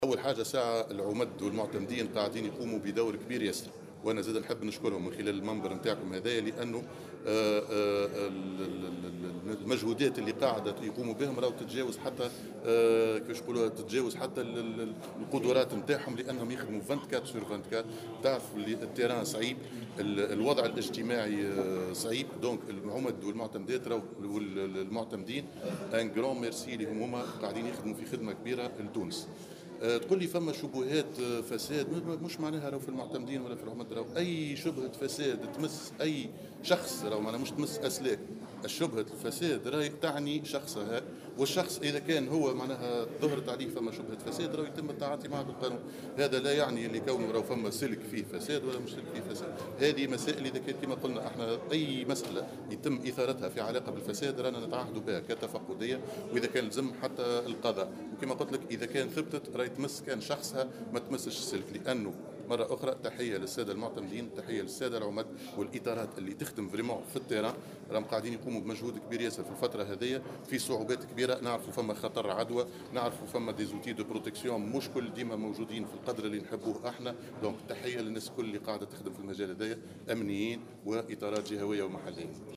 وأضاف الوزير في تصريح اليوم لـ"الجوهرة أف أم" على هامش زيارته لولاية سوسة للإشراف على تنصيب الوالية الجديدة، أنهم يعملون دون انقطاع في ظل خطر انتشار عدوى فيروس "كورونا"، مشيرا إلى أنه سيتم محاسبة كل من يثبت تجاوزه للقانون وتتبعه قضائيا.